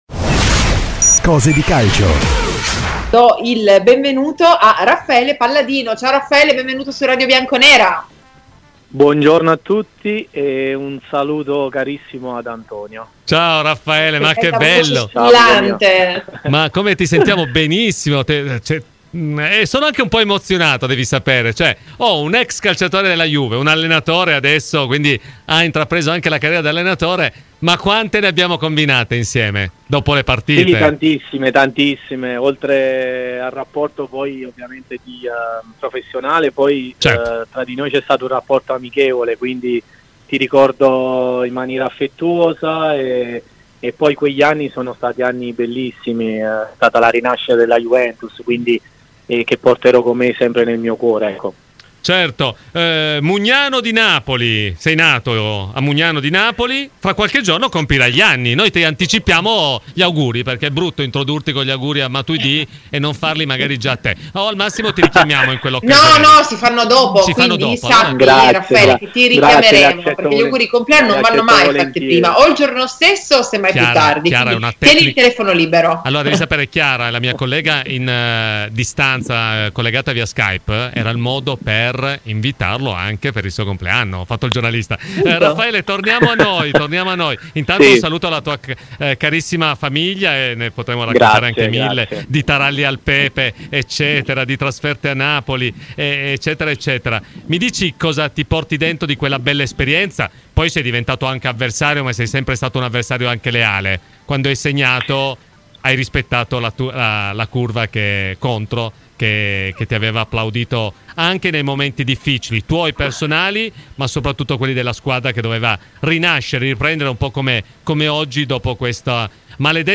Parola di Raffaele Palladino , l'ex giocatore della Juve intervento a Radio Bianconera durante 'Cose di calcio.